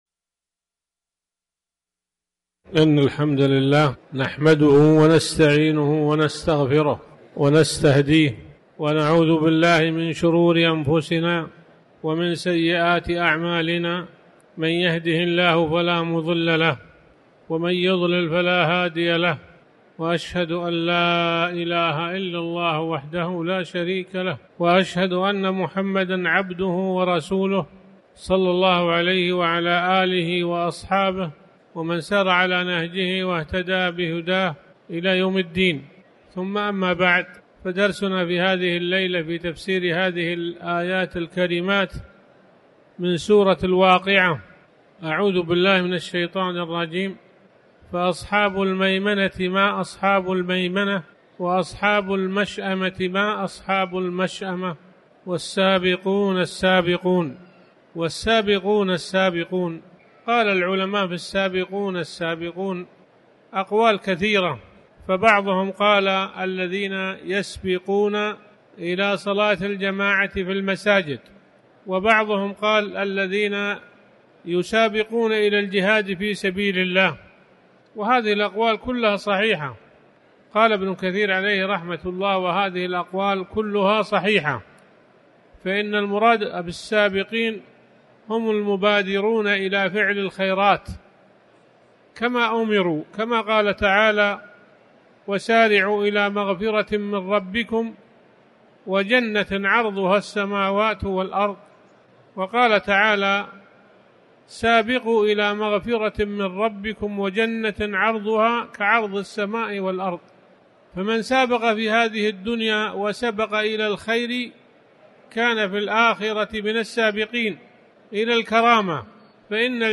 تاريخ النشر ١٠ شعبان ١٤٤٠ هـ المكان: المسجد الحرام الشيخ